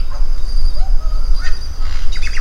yoo_hoo_lyrebird
yoo_hoo_lyrebird.mp3